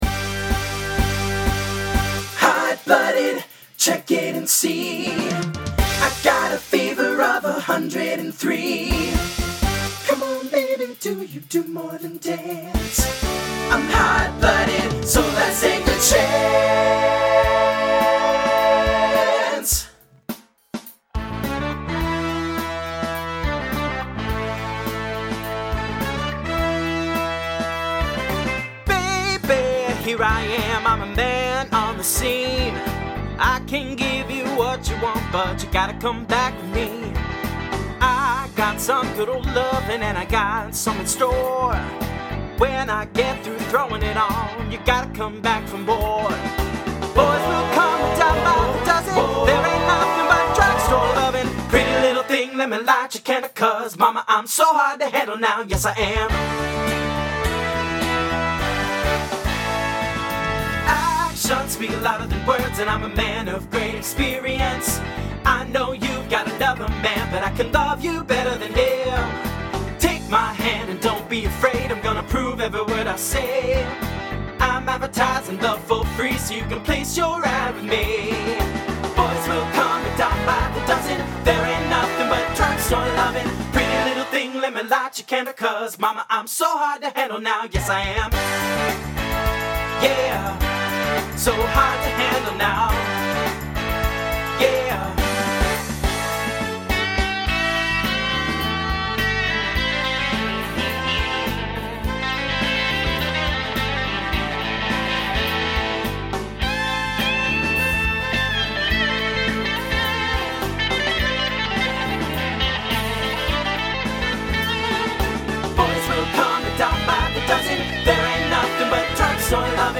Voicing TTB Instrumental combo Genre Rock